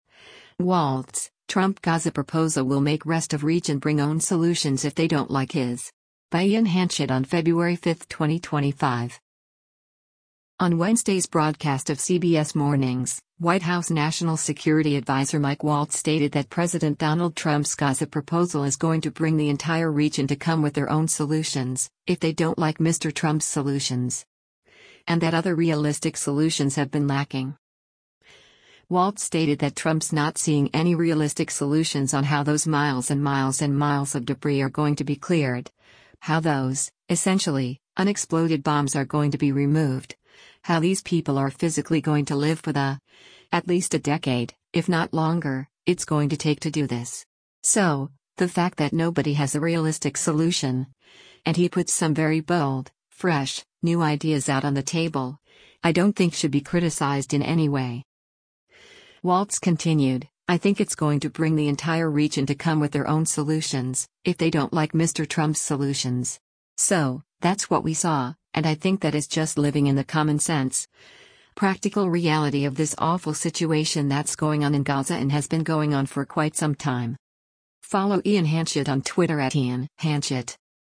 On Wednesday’s broadcast of “CBS Mornings,” White House National Security Adviser Mike Waltz stated that President Donald Trump’s Gaza proposal is “going to bring the entire region to come with their own solutions, if they don’t like Mr. Trump’s solutions.” And that other realistic solutions have been lacking.